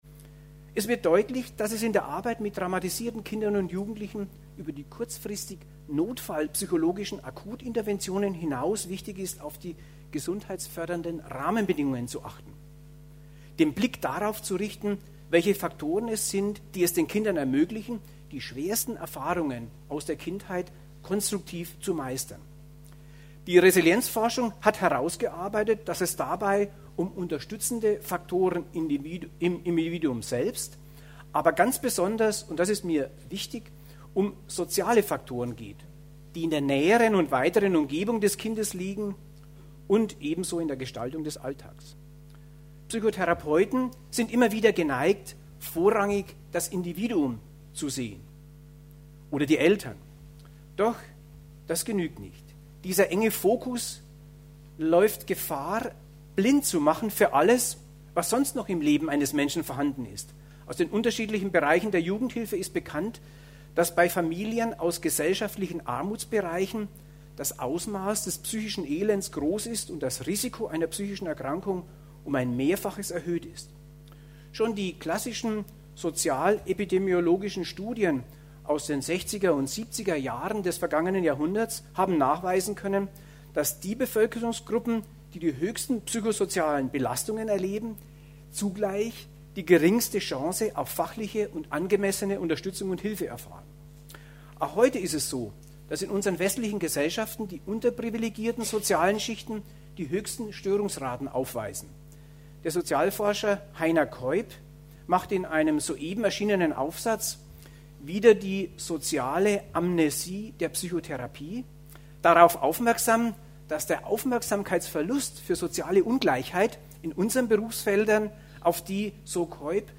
MP3-Downloads des Salzburger Vortrags:
03_Resilienz-Vortrag_Salzburg.mp3